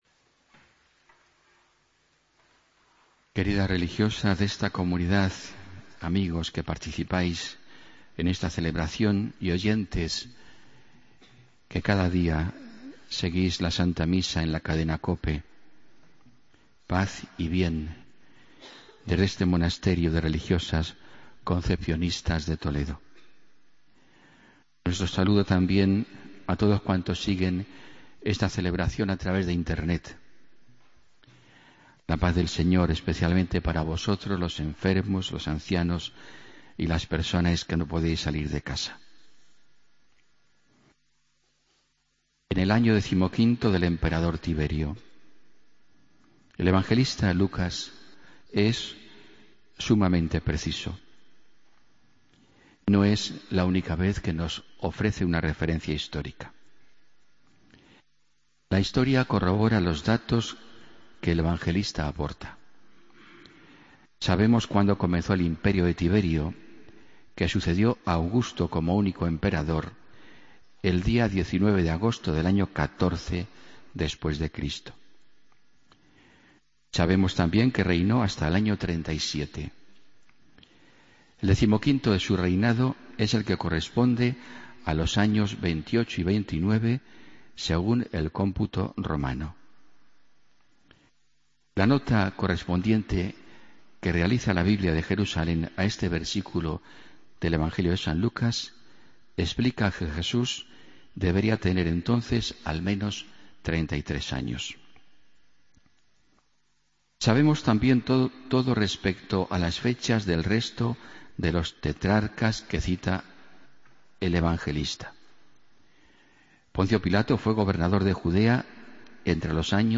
Homilía del domingo 6 de diciembre de 2015